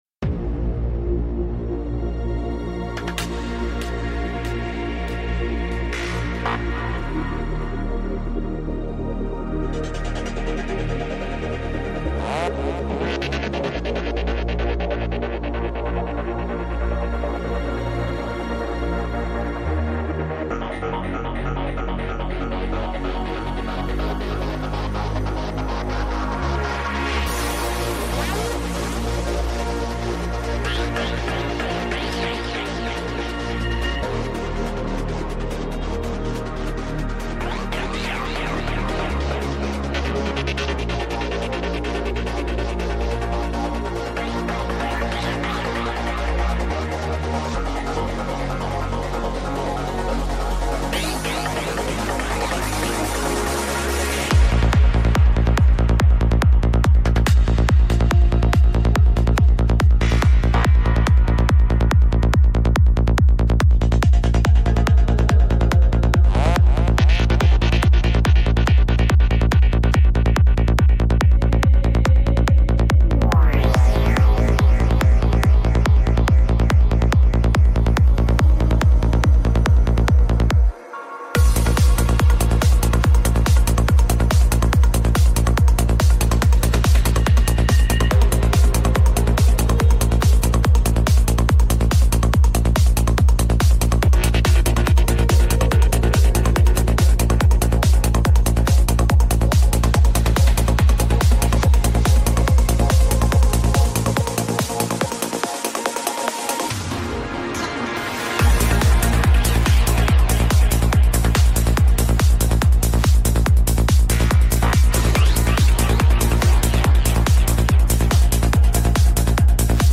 Альбом: Psy-Trance